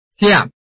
拼音查詢：【饒平腔】siab ~請點選不同聲調拼音聽聽看!(例字漢字部分屬參考性質)